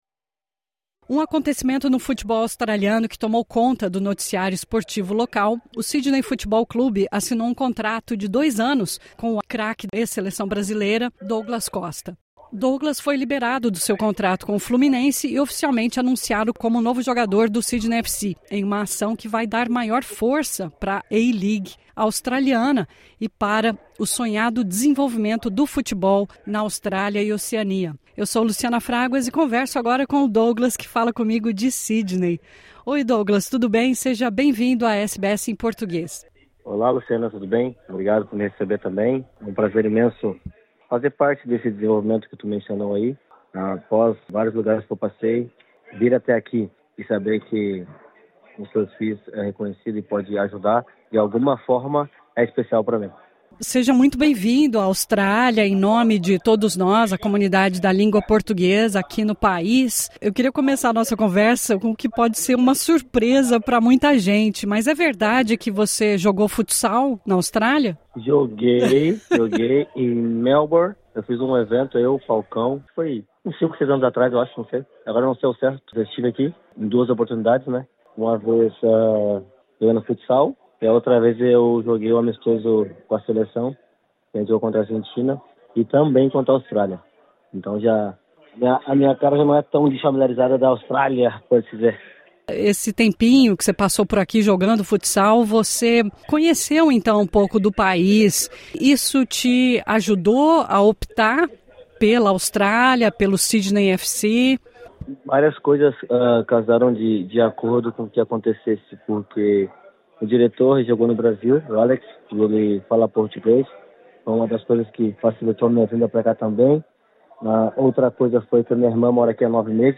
Douglas Costa spoke with SBS Portuguese ahead of his highly anticipated Sydney FC debut.